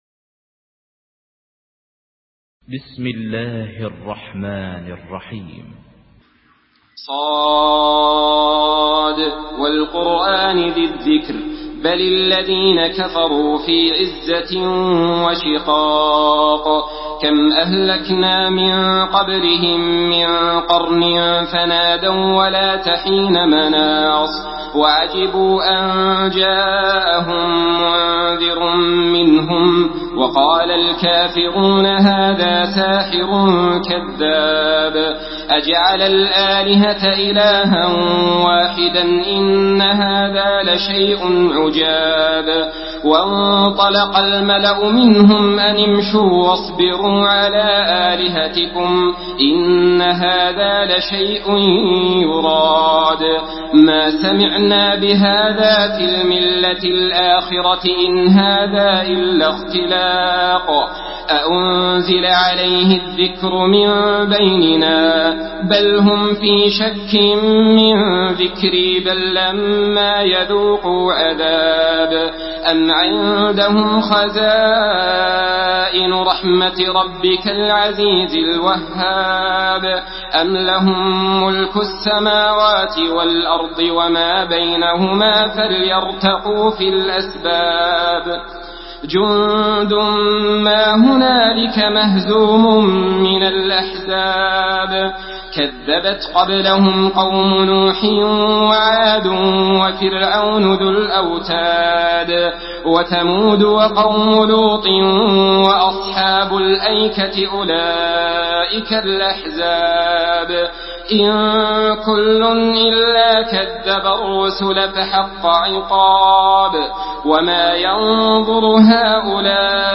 Surah ص MP3 by صالح آل طالب in حفص عن عاصم narration.
مرتل حفص عن عاصم